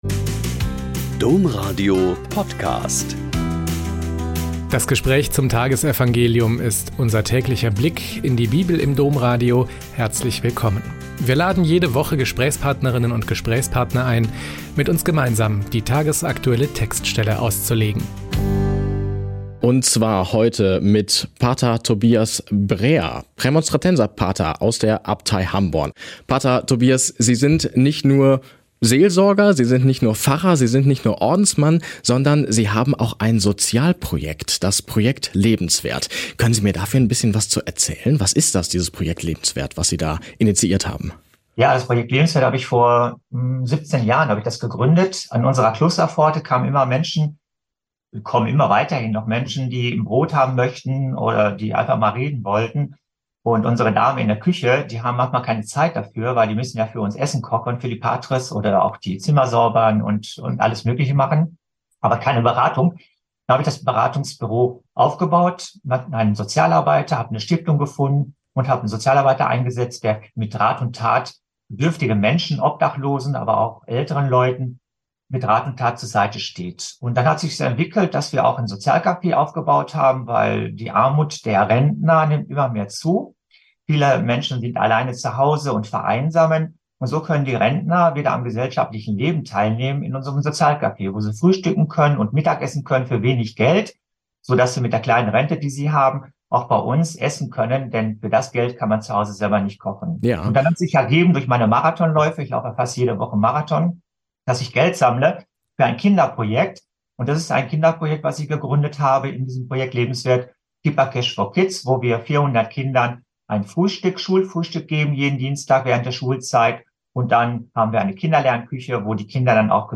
Joh 19,16-30 - Gespräch